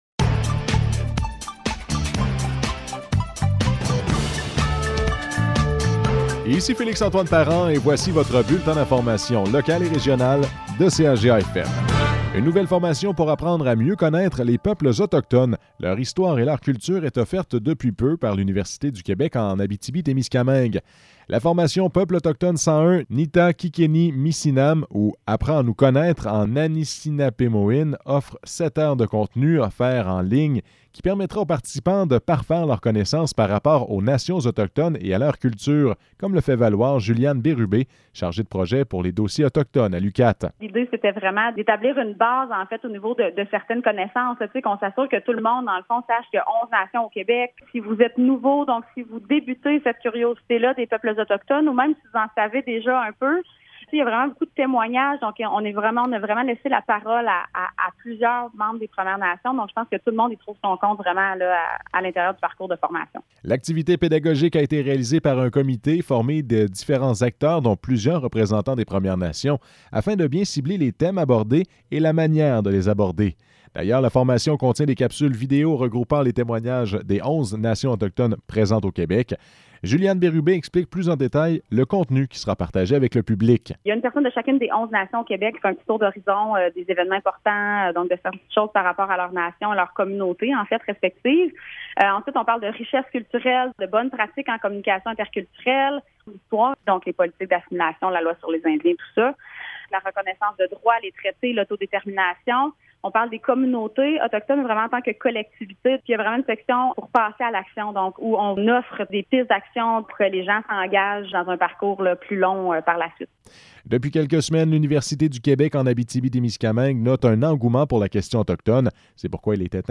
Nouvelles locales - 27 juin 2022 - 12 h